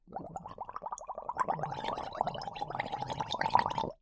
Garlge Sound Effects MP3 Download Free - Quick Sounds